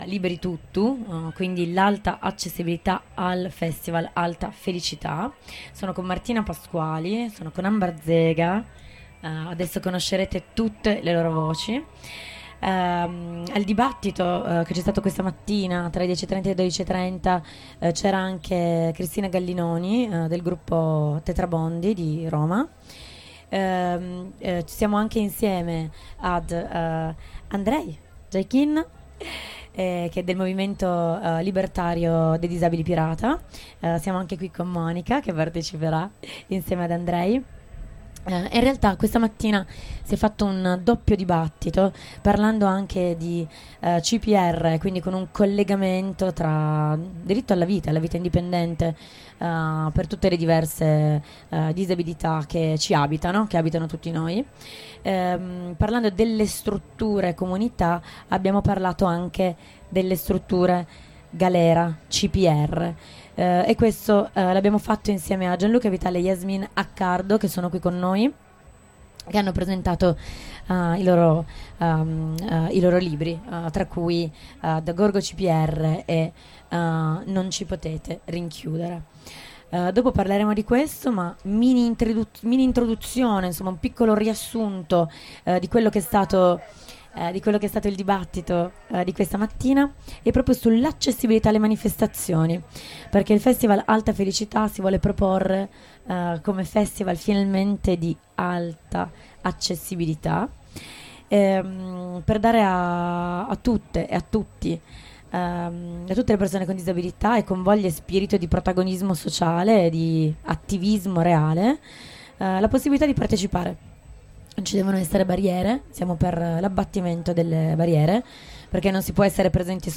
Al centro: la libertà di scegliere, di essere ascoltatu, e il diritto alla partecipazione. Un’intensa intervista, collettiva e necessaria: perché senza giustizia, accessibilità e libertà per tuttu, nessunə è veramente liberə.